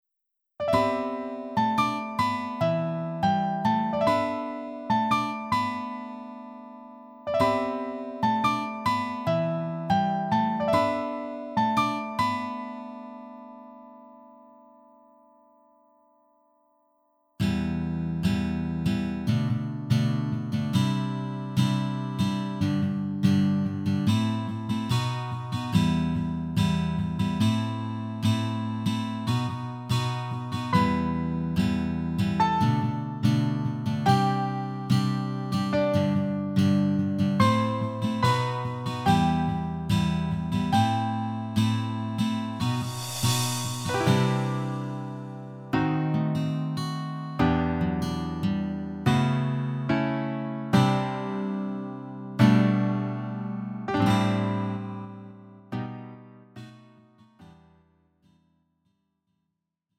음정 원키 3:05
장르 가요 구분 Lite MR